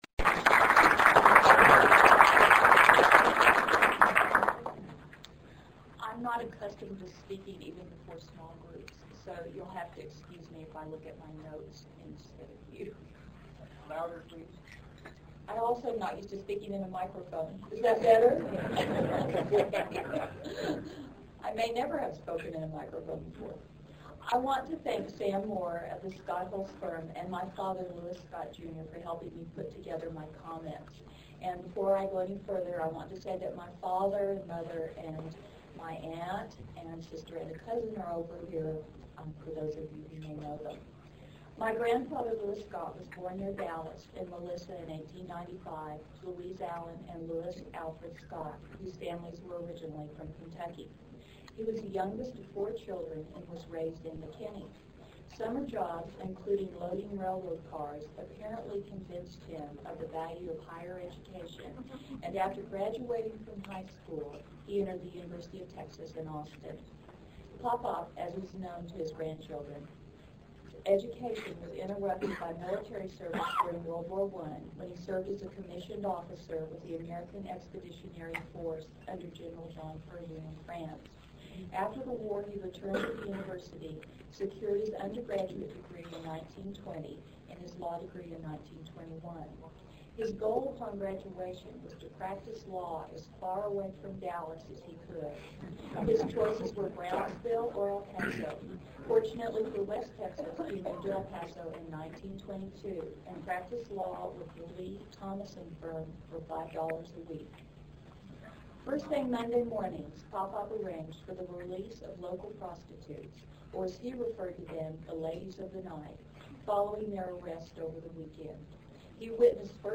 El Paso Bar Presidents Presentation
Photos of the Bar Luncheon Honoring Bar Presidents